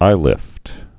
(īlĭft)